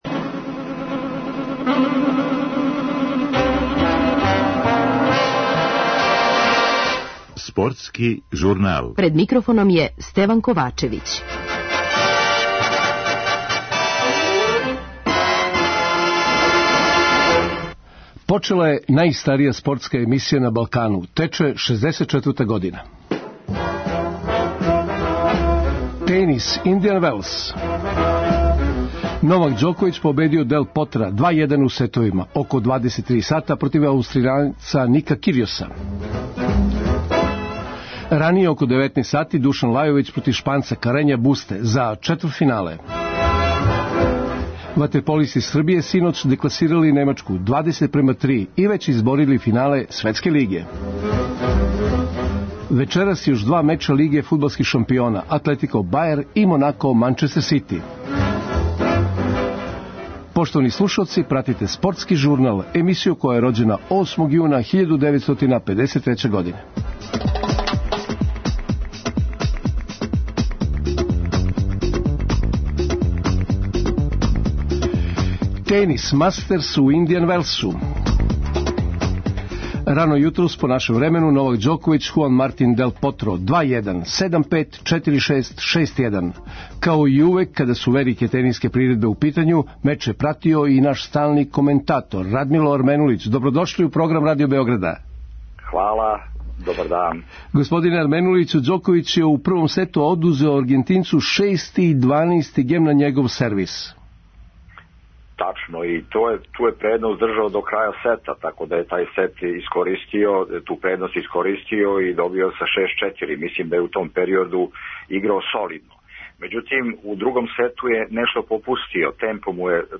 Гост коментатор